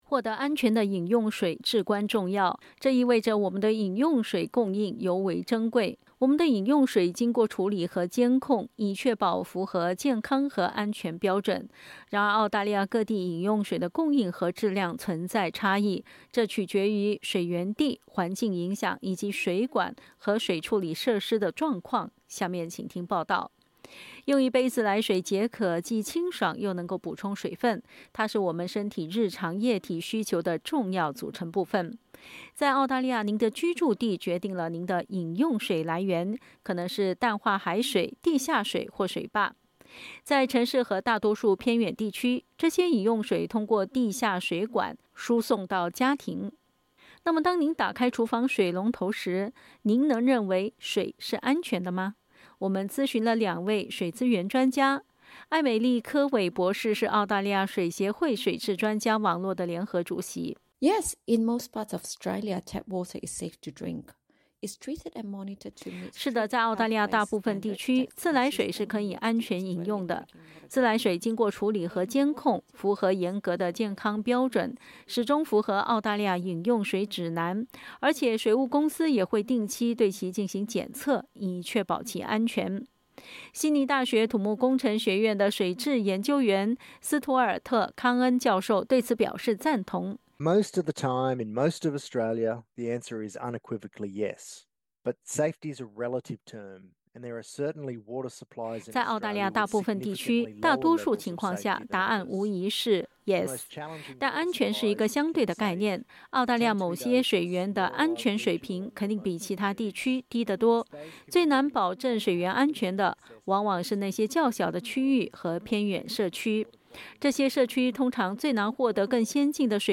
SBS 普通话电台
我们咨询了两位水资源专家。